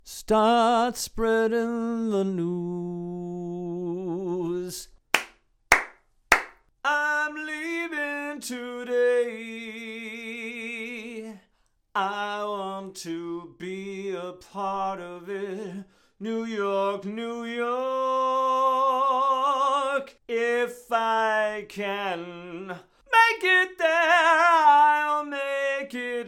Here is your first section with three reverbs, the first is an impulse response of a plate reverb, the second is a IR of a Vienna hall with a longer decay, the third is an IR of a medium tiled room with a much shorter decay. All are at the same level.
Note that this is on top of the short slap echo you already have, so it's not ideal.